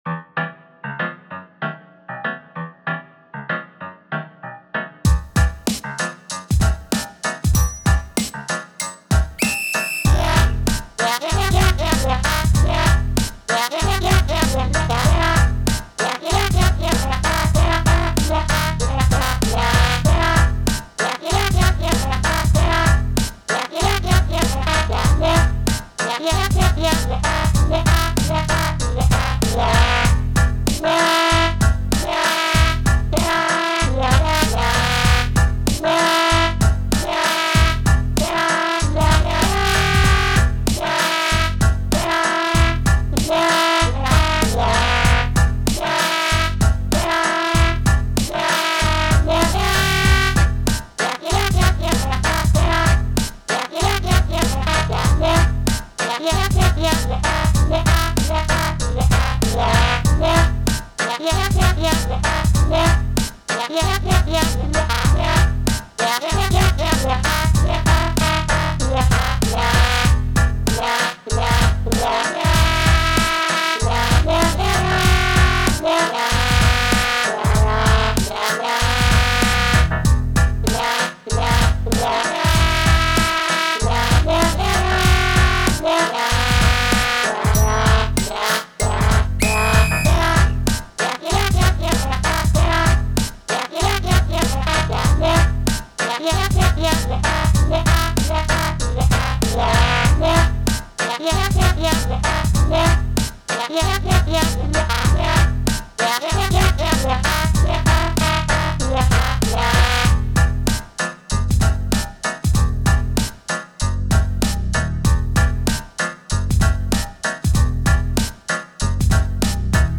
トランペットが特徴的で、ヨレたリズムのリードフレーズをグロウル奏法で奏でている。